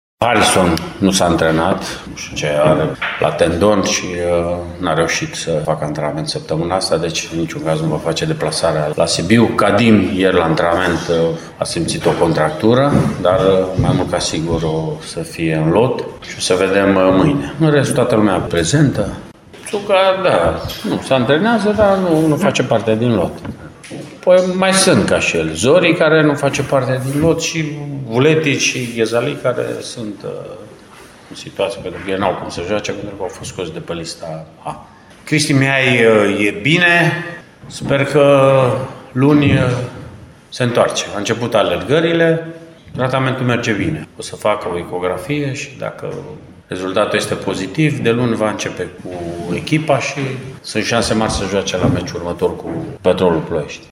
Mircea Rednic a vorbit și despre problemele de lot cu care se confruntă în momentul de față.